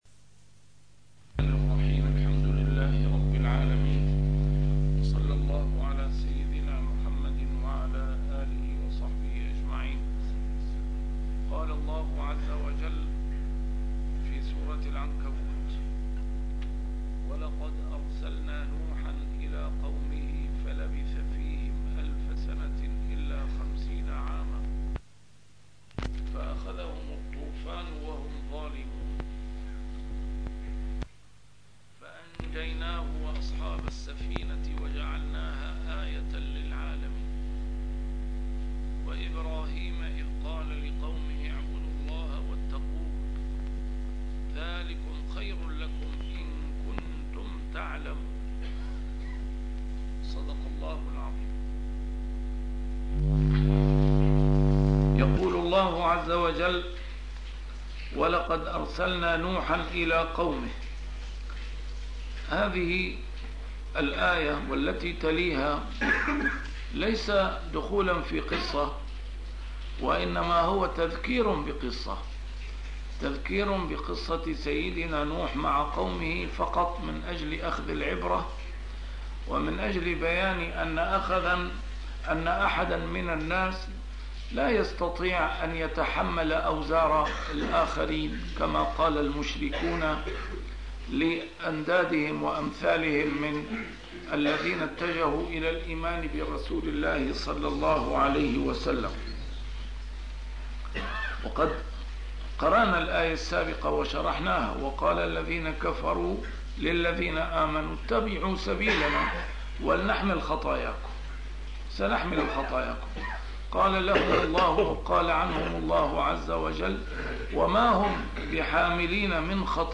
A MARTYR SCHOLAR: IMAM MUHAMMAD SAEED RAMADAN AL-BOUTI - الدروس العلمية - تفسير القرآن الكريم - تسجيل قديم - الدرس 294: العنكبوت 14-15